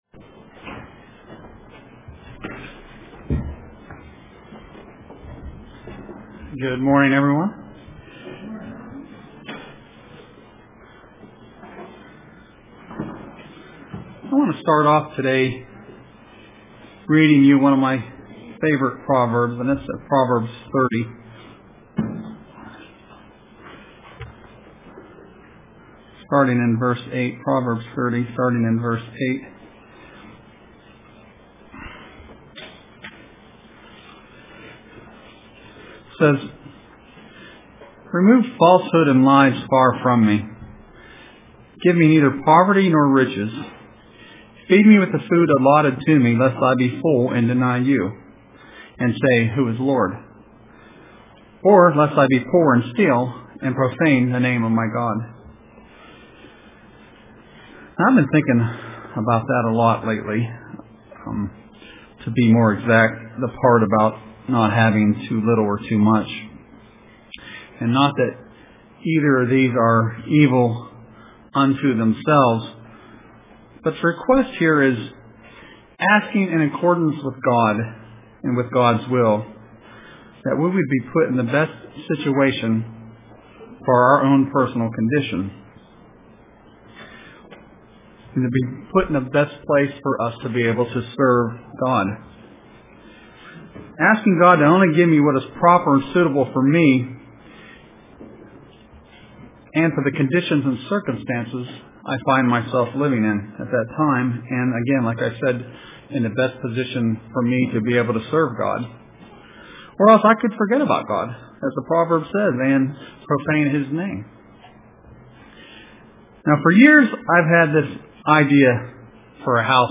Print Choose the Better Part UCG Sermon Studying the bible?